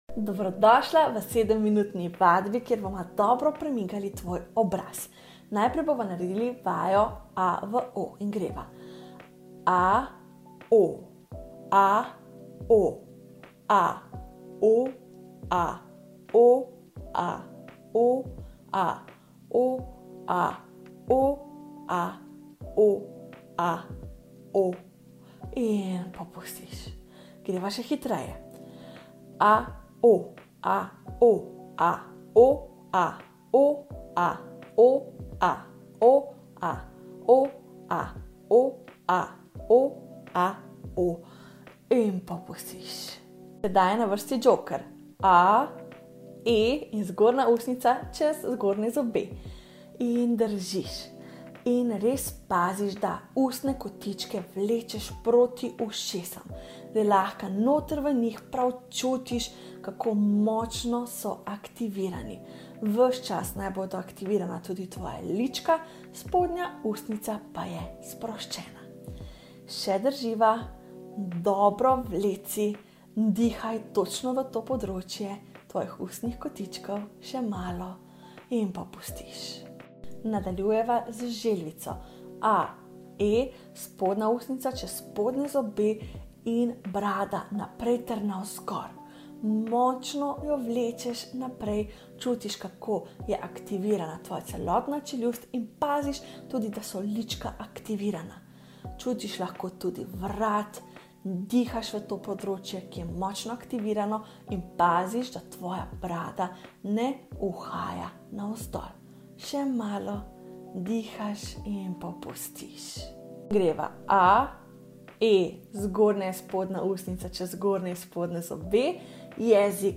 Ispod je audio snimka vježbe koju možete reproducirati u bilo kojem trenutku i izvesti 7-minutni ljetni trening joge lica u pokretu, vođeni mojim glasom.